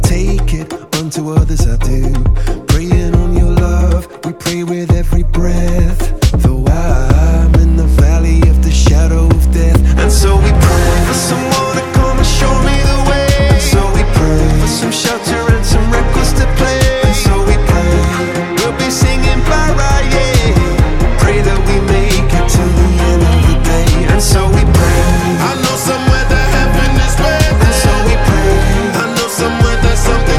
Dance pop, hymnes de stade et ballade amoureuse